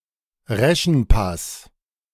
Reschen Pass (German: Reschenpass, pronounced [ˈrɛʃn̩ˌpas]
De-Reschenpass.ogg.mp3